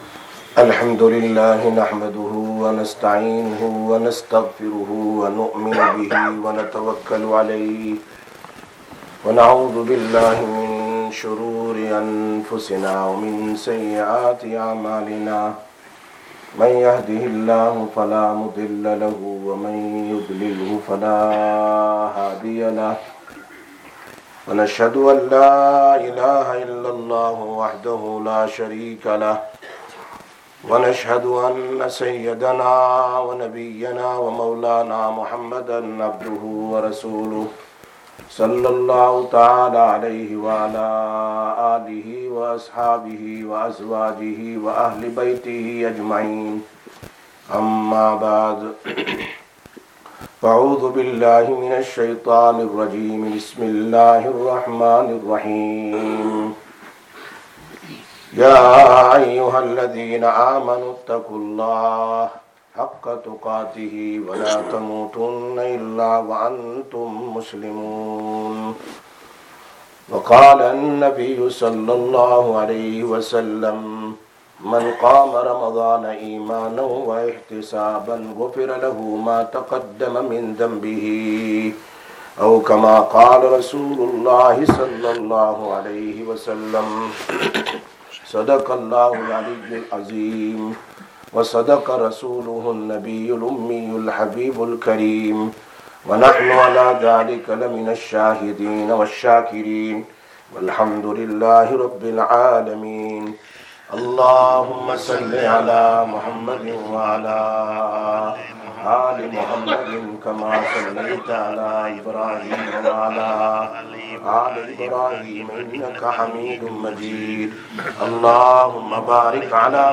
05/05/2024 Jumma Bayan, Masjid Quba